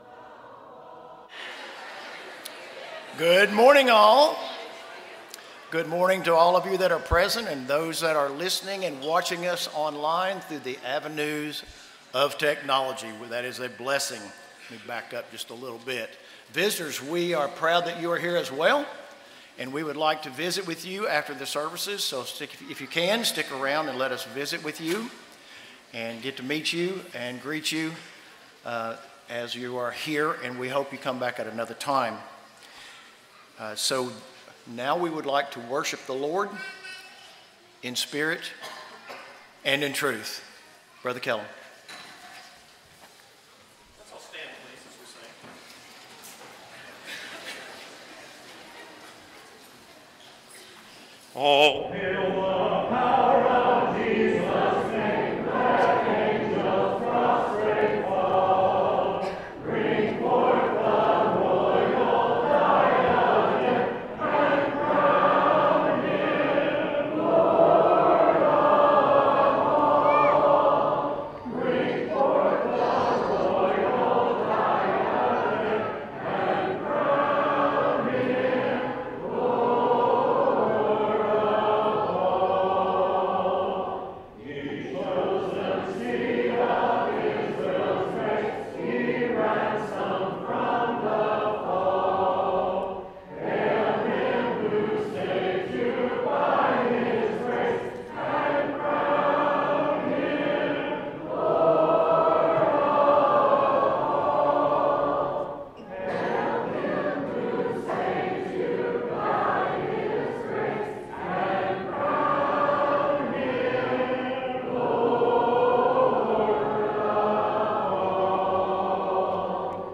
John 13:35, English Standard Version Series: Sunday AM Service